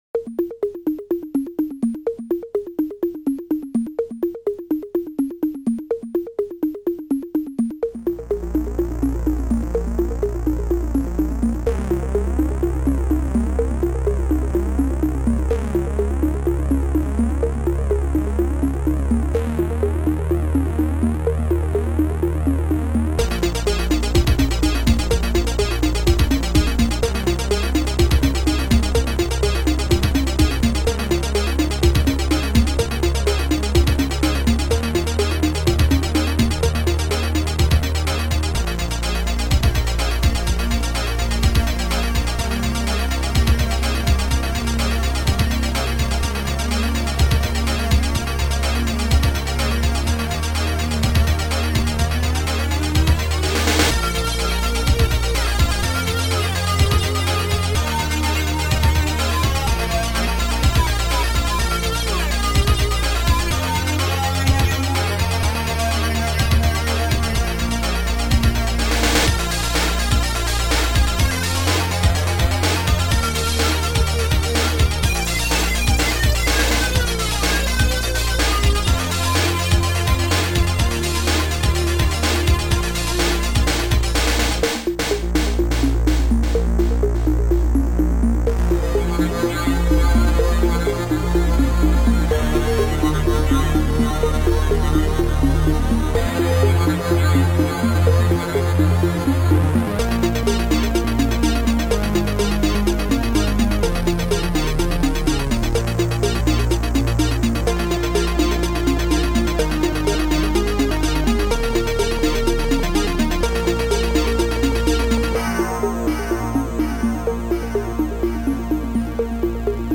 Sound Format: Noisetracker/Protracker
Sound Style: Groovy